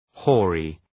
Προφορά
{‘hɔ:rı}